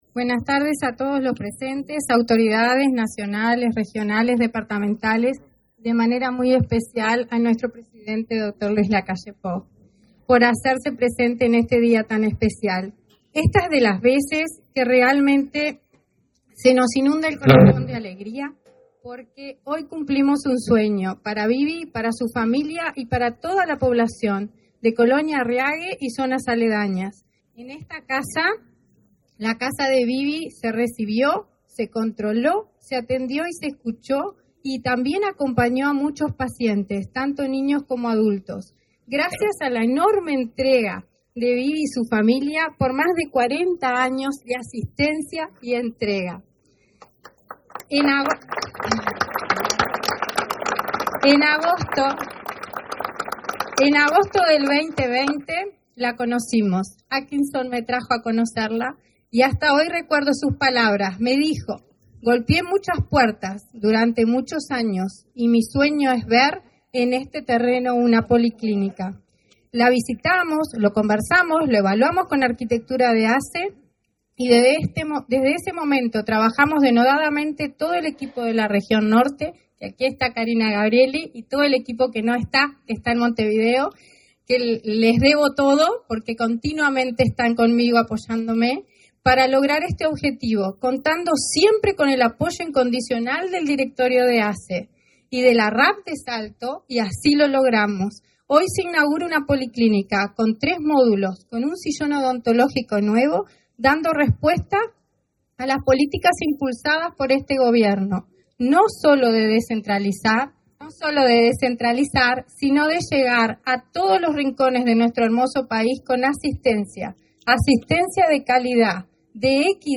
Conferencia de prensa por la inauguración de la policlínica en Colonia Harriague
Con la presencia del presidente Luis Lacalle Pou, se realizó, este 20 de abril, la inauguración de la policlínica en Colonia Harriague, Salto.